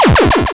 hit_hurt.wav